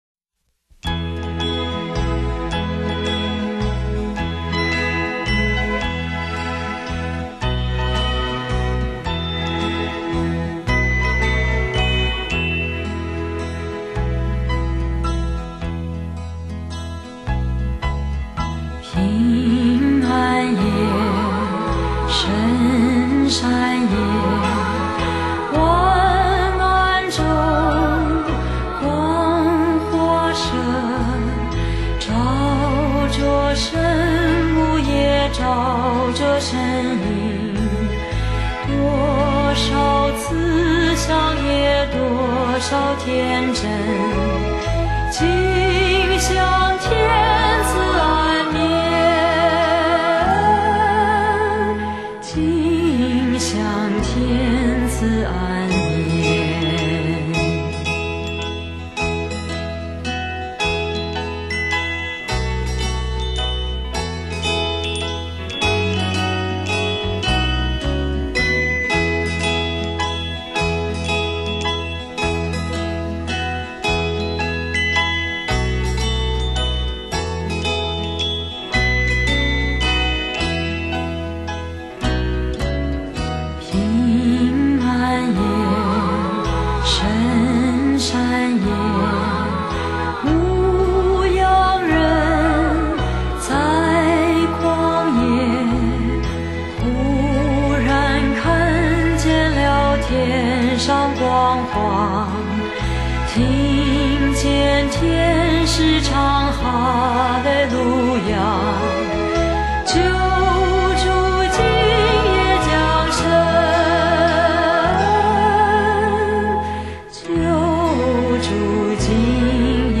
浑厚的歌声